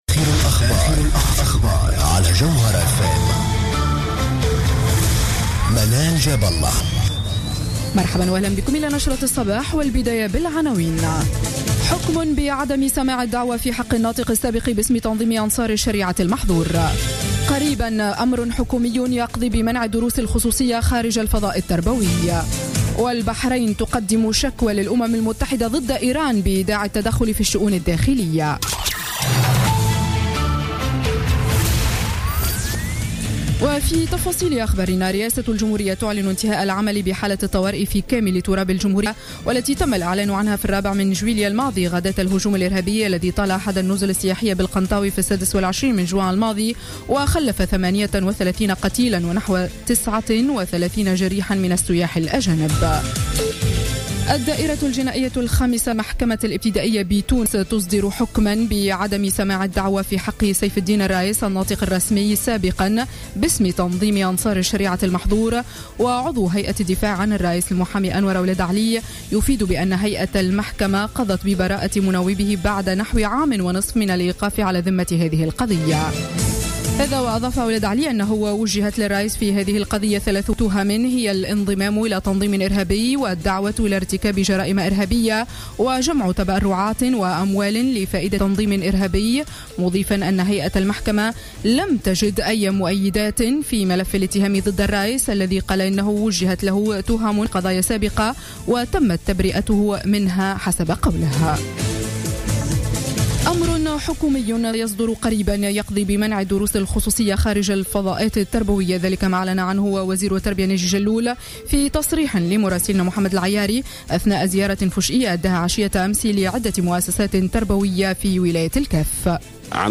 نشرة أخبار السابعة صباحا ليوم السبت 3 أكتوبر 2015